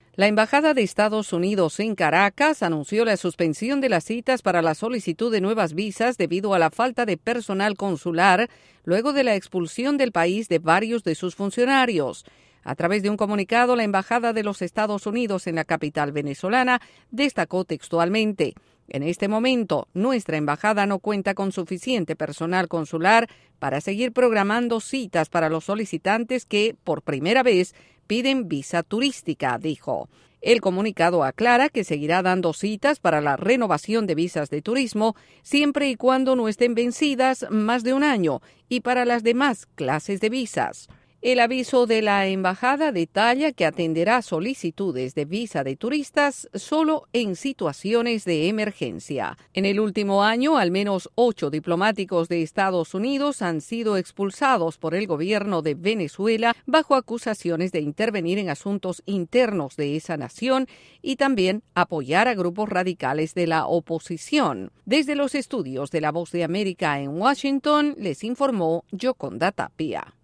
La embajada de Estados Unidos anunció la primera reducción de servicios consulares en Caracas. Desde la Voz de América en Washington DC informa